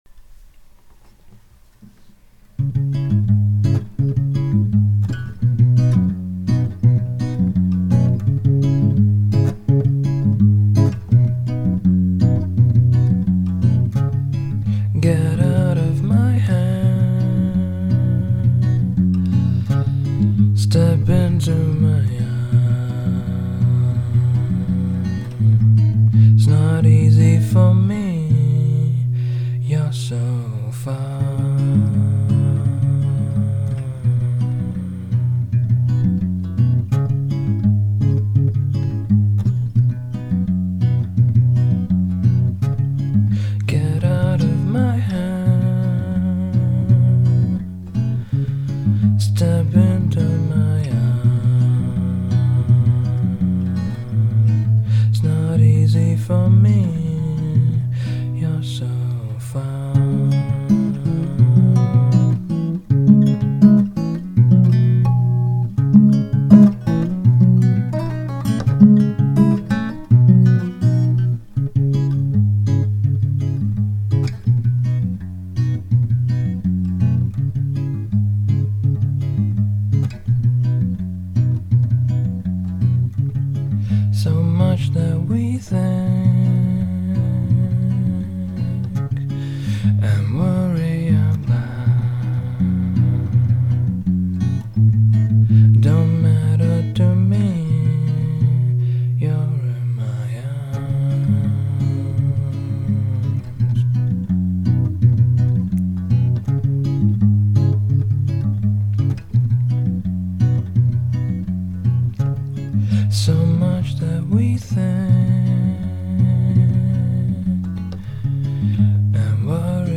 My music is a mix of bluesy, folksy and North-Indian classical stuff.
mp3 video lyrics (May 2008)   This is a cute little song with a slightly reggae beat to it.